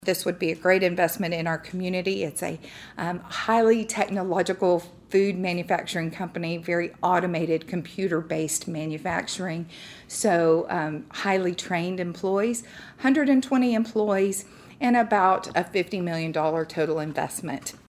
At Thursday’s meeting of the Brenham City Council